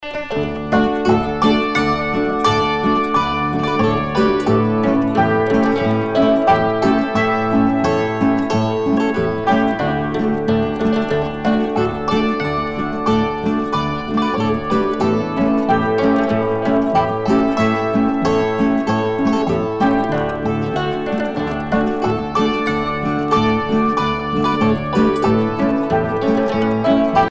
danse : ländler